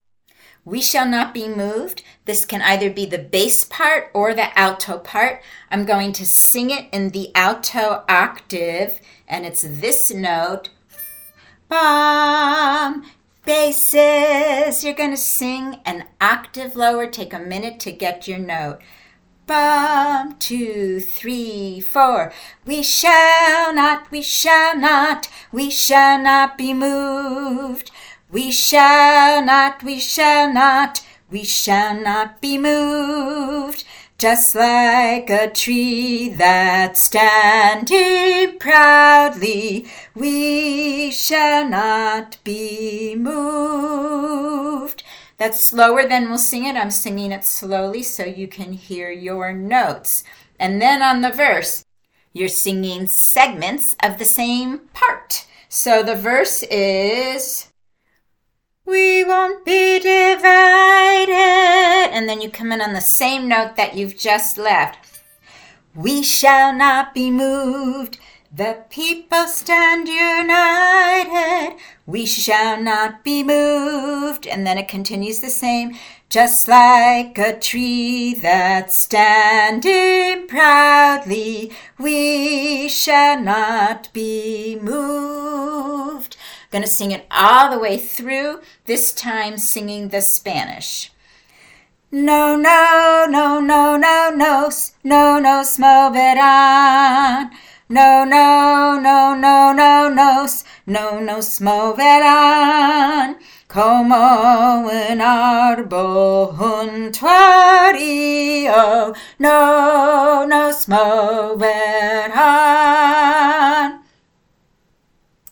bass-alto
We Shall Not Be Moved bass-alto.mp3